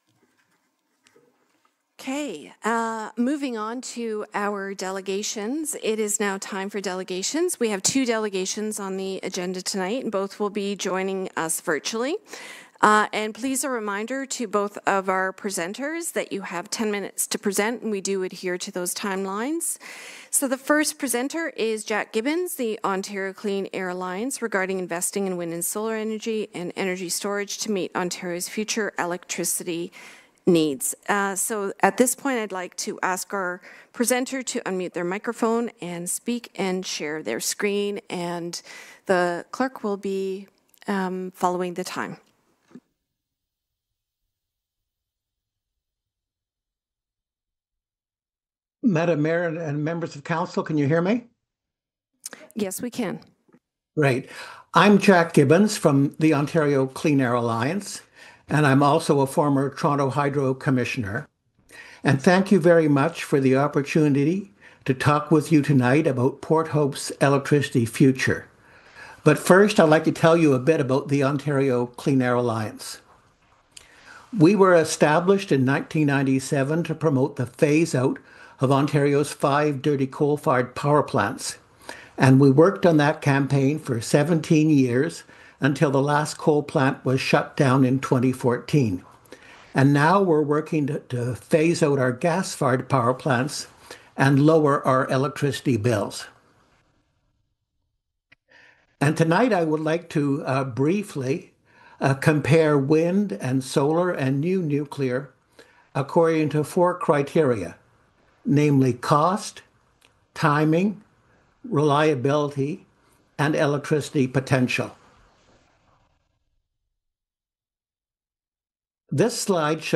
PH-CLEAN-AIR-ALLIANCE-PRESENTATION-TO-COUNCIL.FINAL_-1.mp3